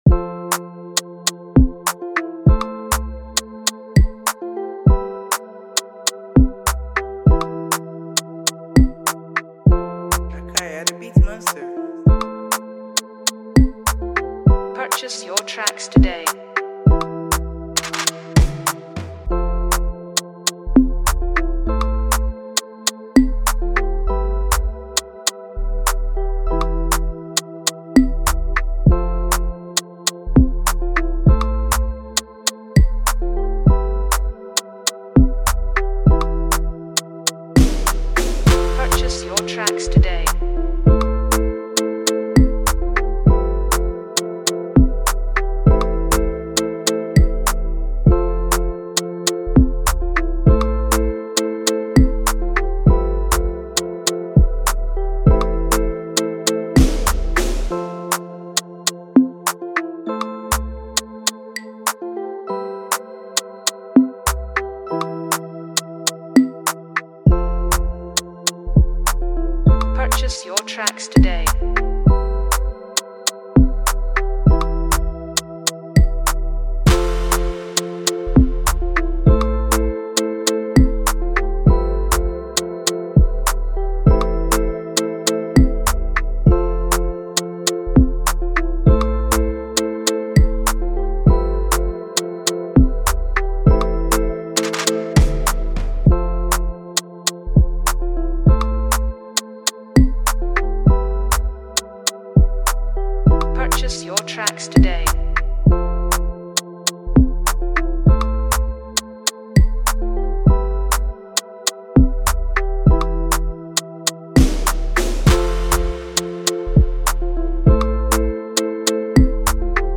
it’s a new afrobeat sound